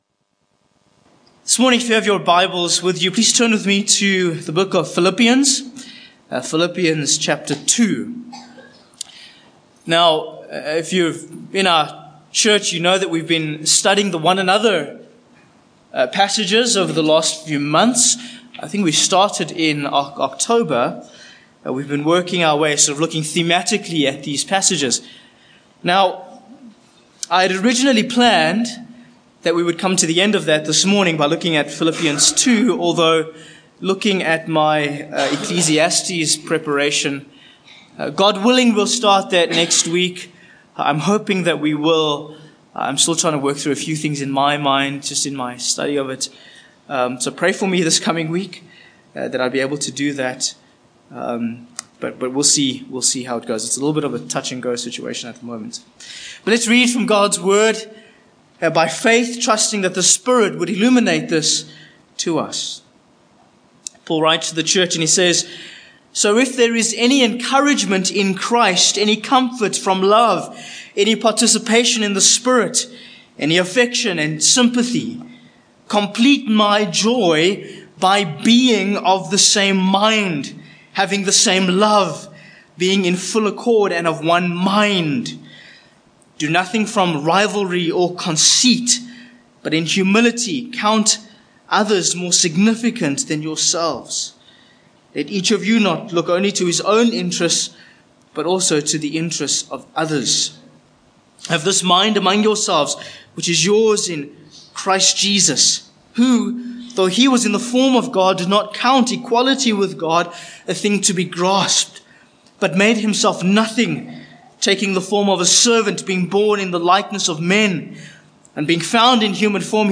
Philippians 2:1-8 Service Type: Morning Passage